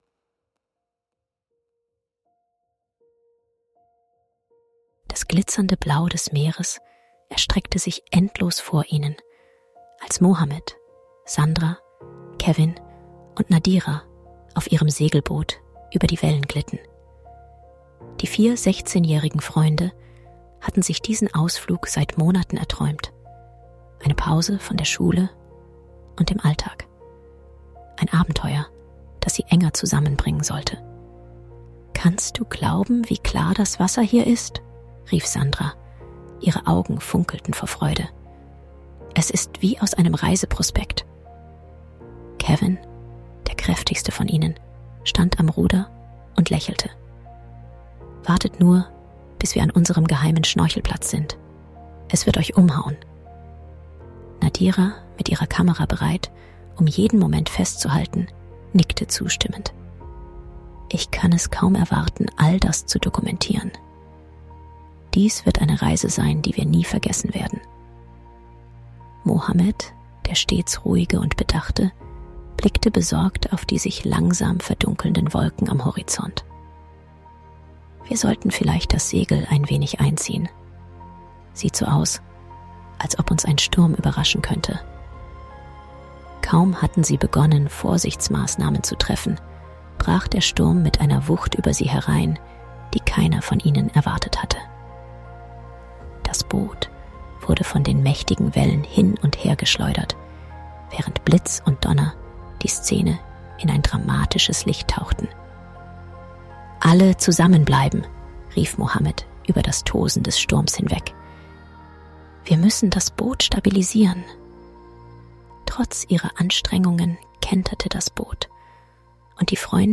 Lässt euch von diesem emotionalen Hörspiel mitreißen!